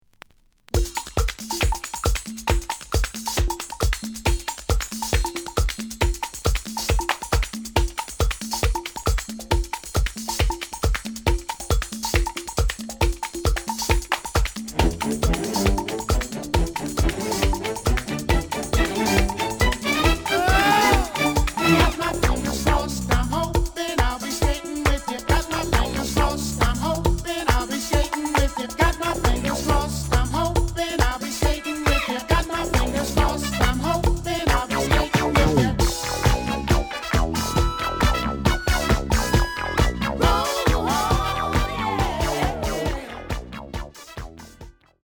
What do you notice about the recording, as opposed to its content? The listen sample is recorded from the actual item.